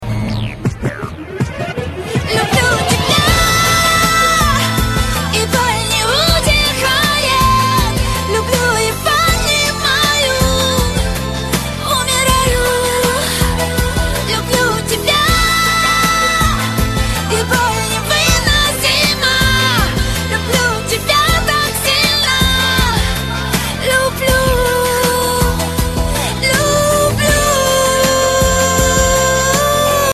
Главная » рингтоны на телефон » поп